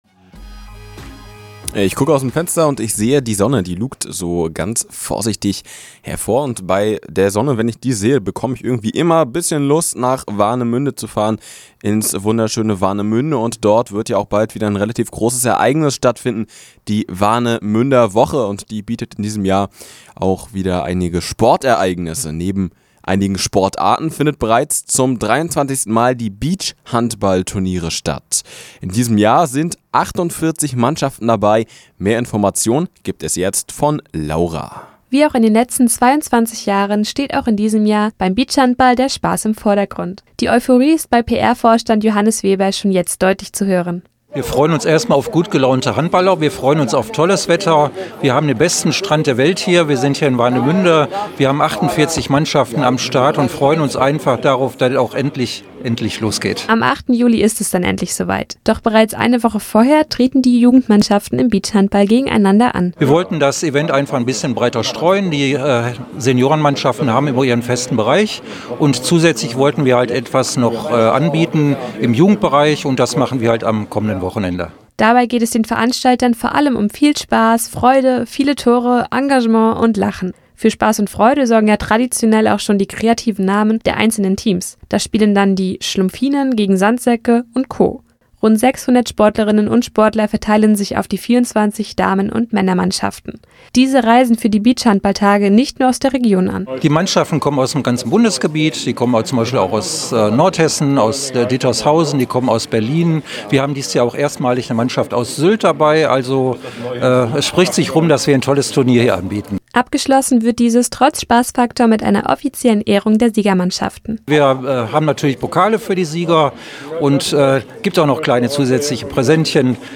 Radio zum Nachhören